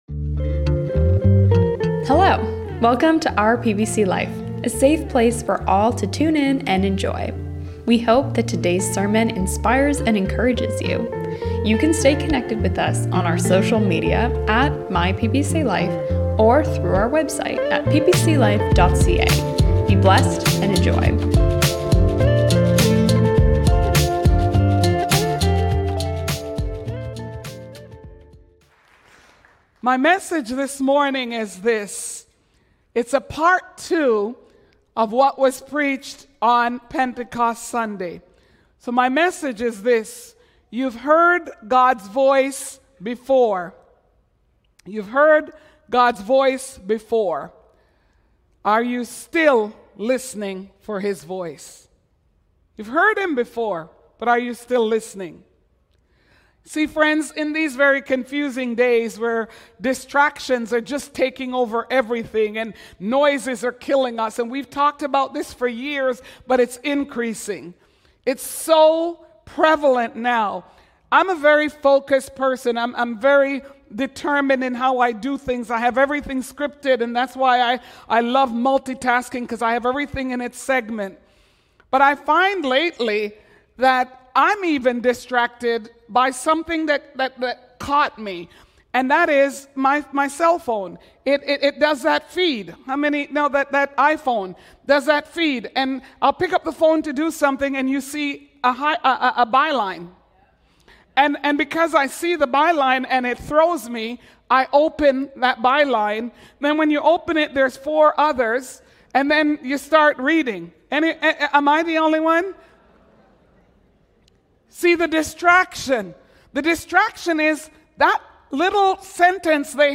We trust that you will enjoy this message, as we begin our summer sermons.